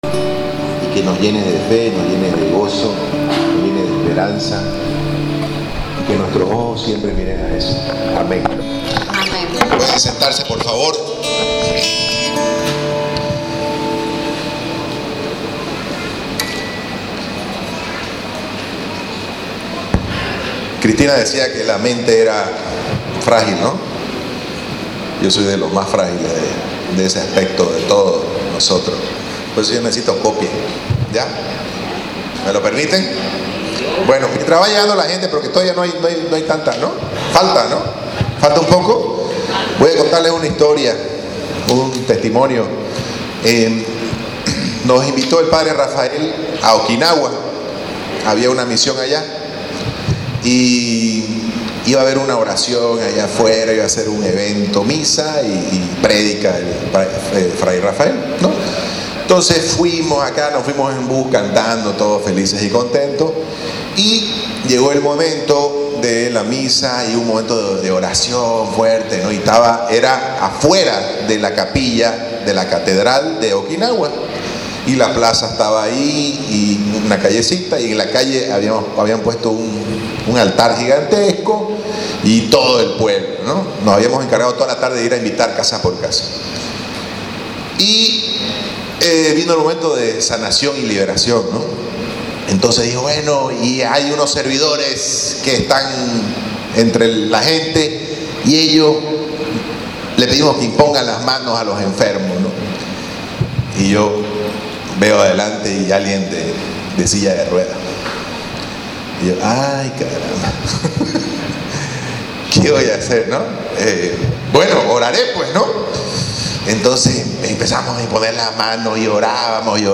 Lugar: Hotel Novotel